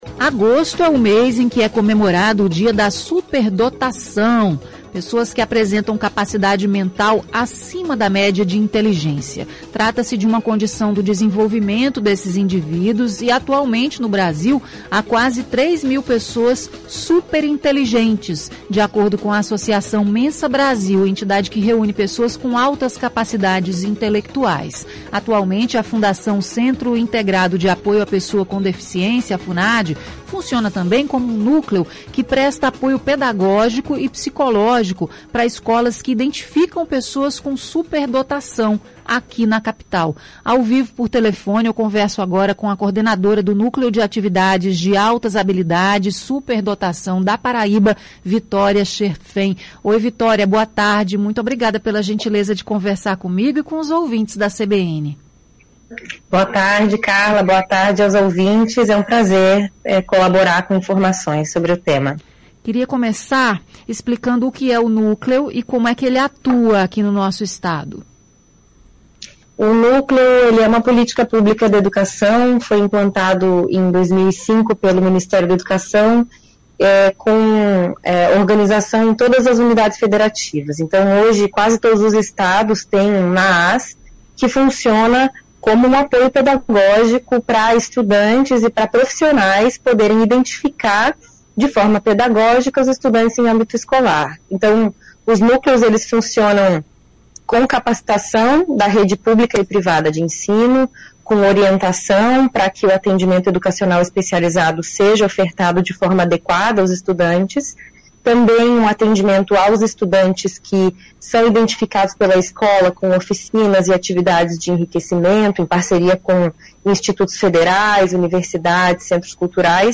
Entrevista: ‘superdotação’